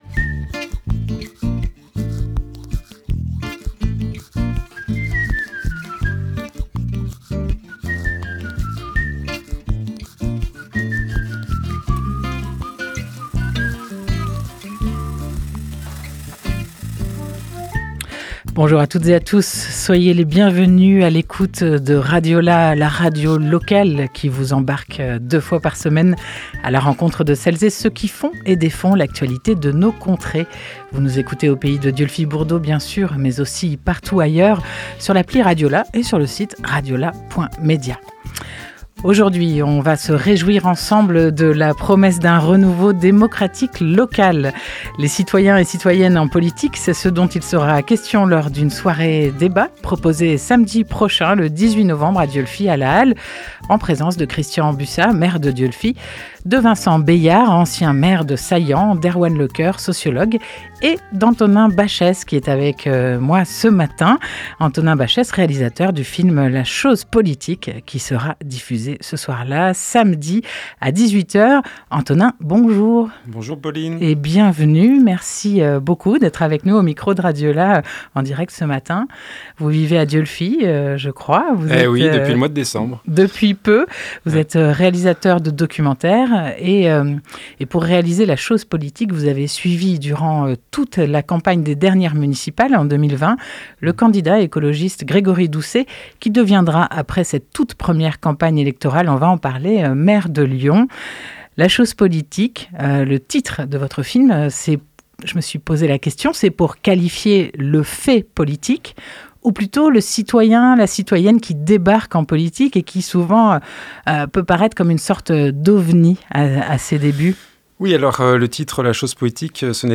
14 novembre 2023 11:42 | Interview